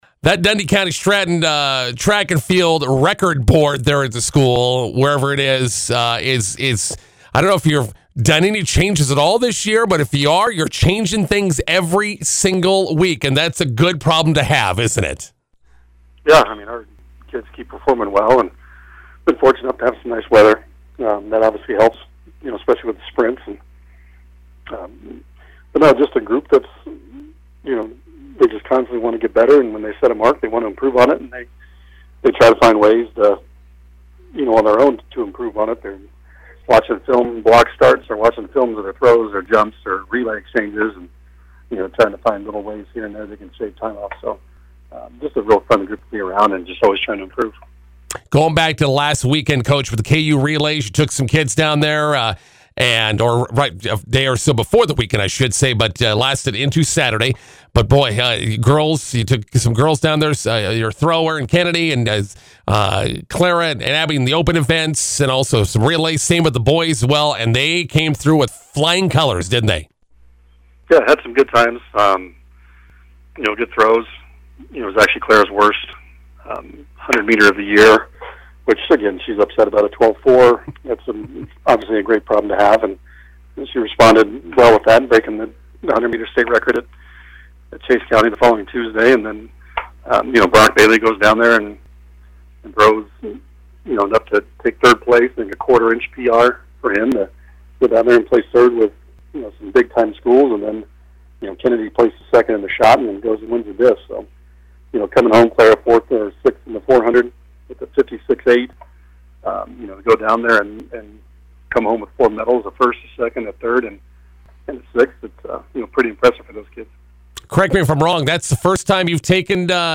INTERVIEW: Dundy County-Stratton Track and Field continues to break state and school records.